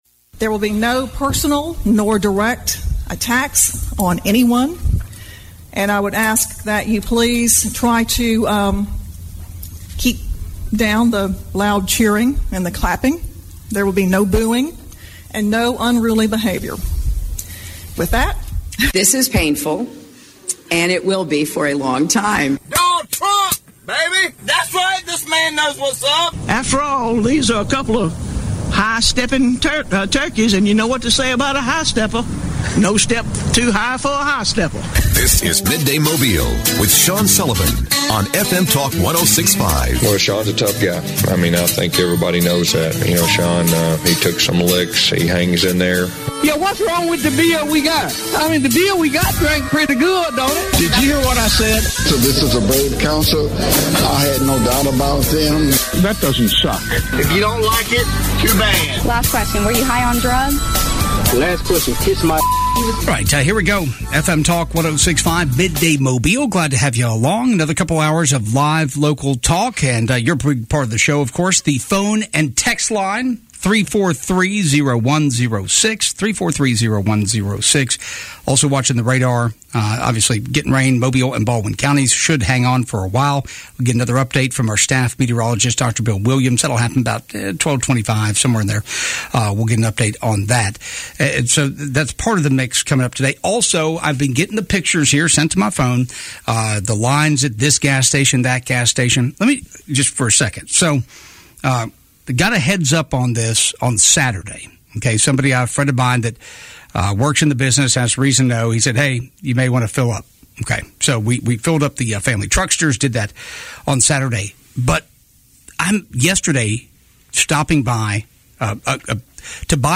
They also discussed medical marijuana and prisons. Callers and texters asked about pistol permit.